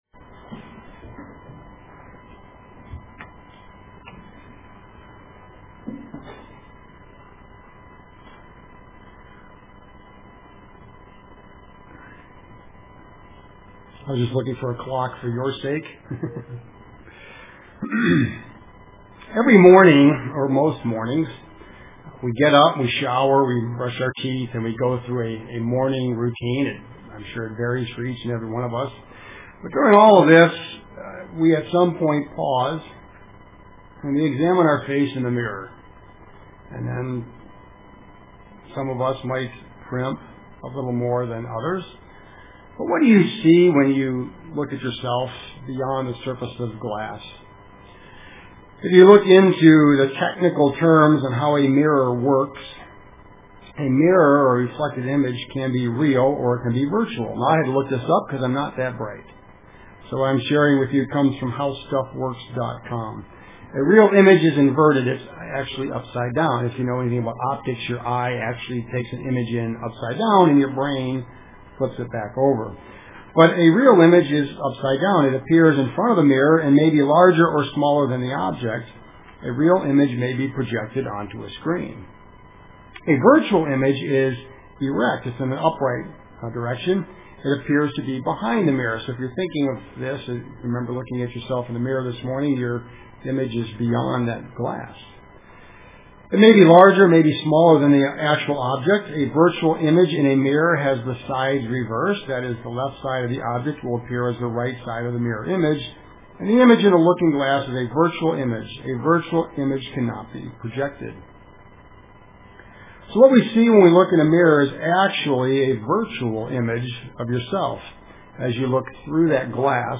Print How's Your Complexion UCG Sermon Studying the bible?